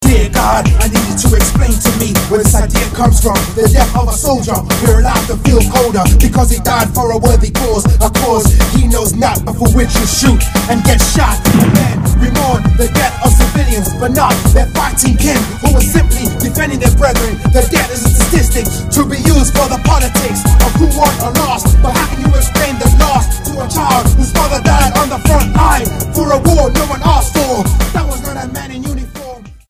STYLE: World
which is chillingly other-worldly
If you're up for some African hip-hop this is for you.